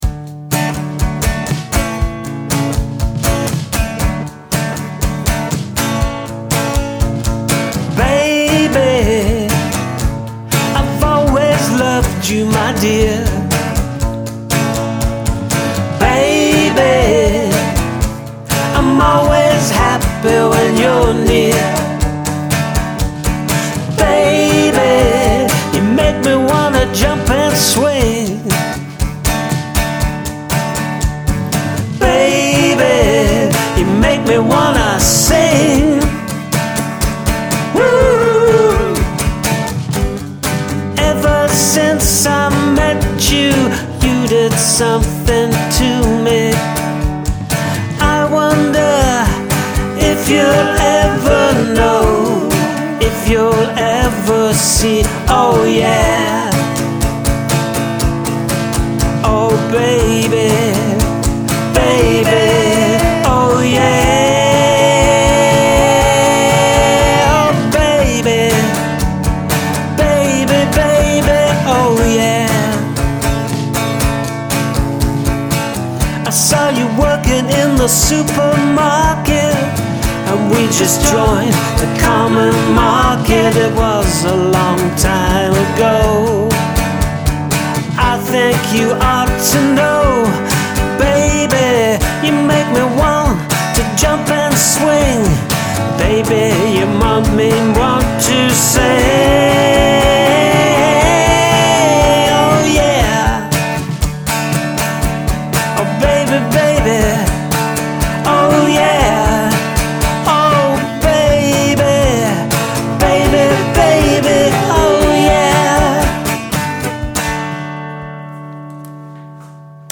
Nice job, got yourself a poptastic number here
Catchy and fun!